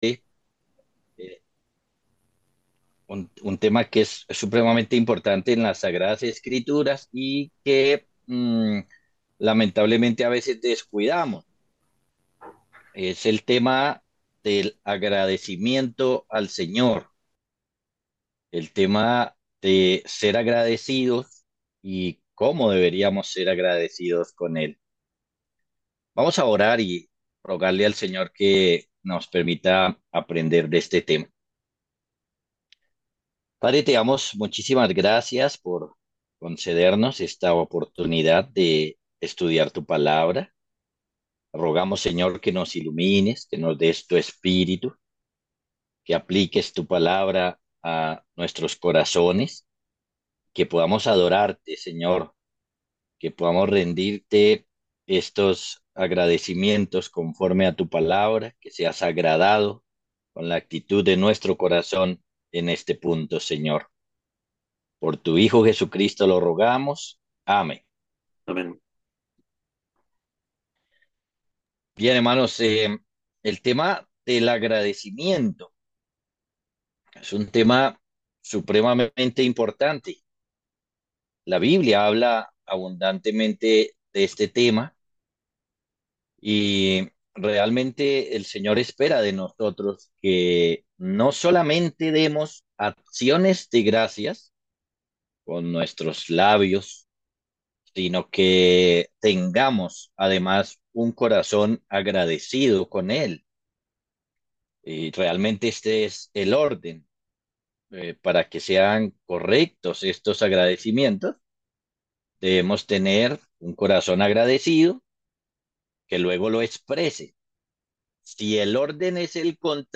Sermones generales